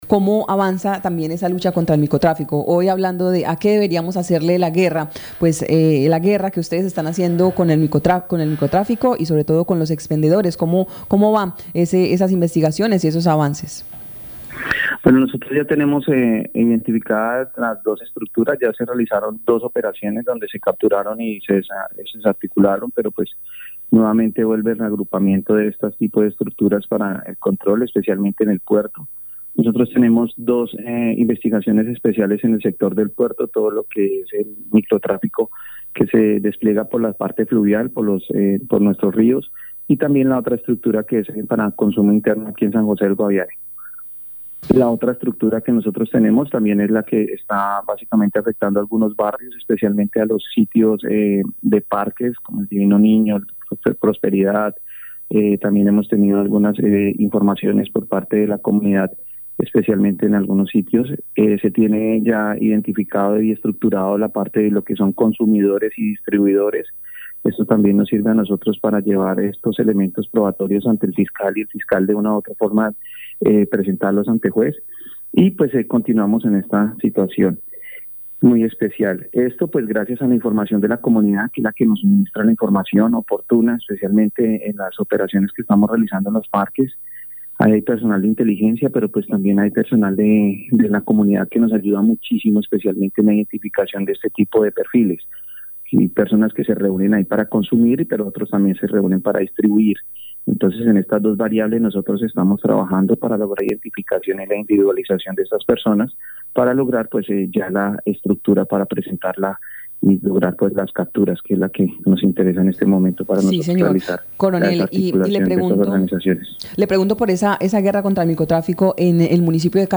Escuche a coronel Ángel Alexander Galvis Ballén, comandante Departamento Policía Guaviare.